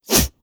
Close Combat Swing Sound 27.wav